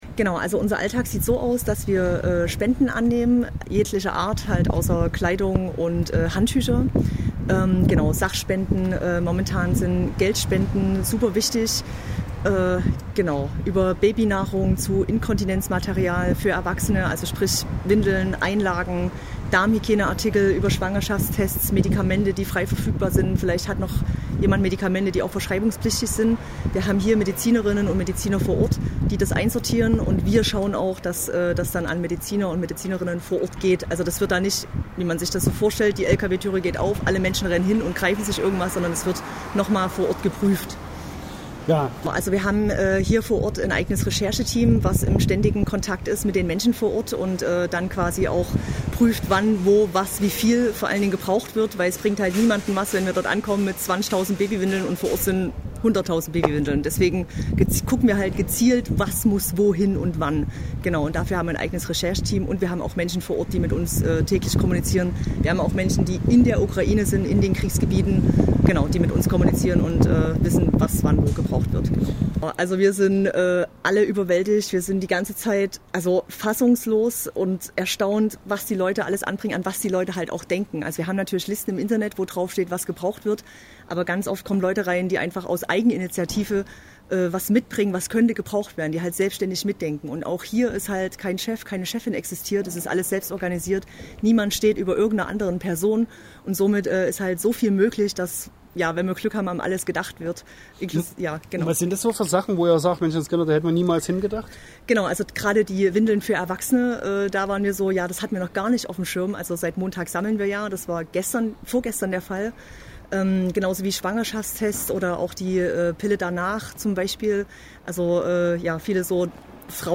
Interview mit Direkthilfe Dresden